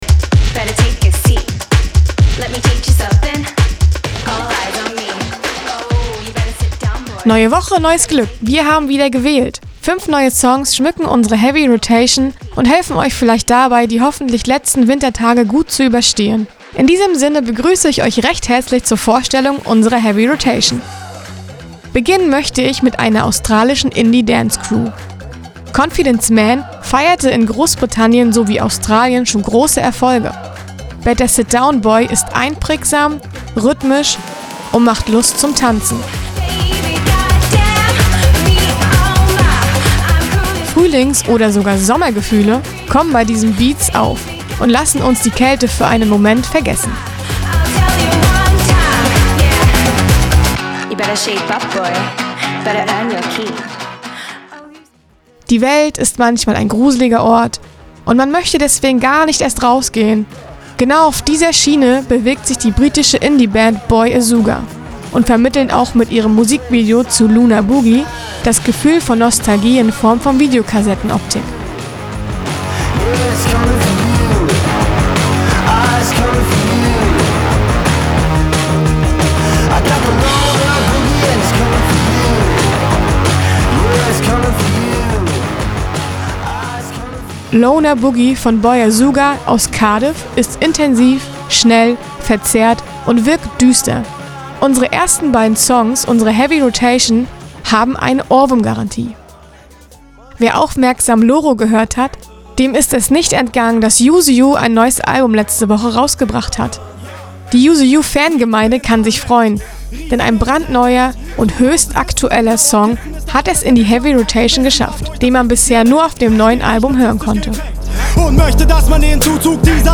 Und hier zum Nachhören der akkustischen Nicensteinigkeit in vollster Kompensation, der auditive Beitrag über die Heavy Rotation dieser Woche: